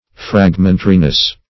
Fragmentariness \Frag"men*ta*ri*ness\, n.
fragmentariness.mp3